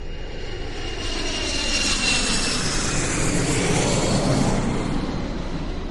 Noise from a jet engine during take off.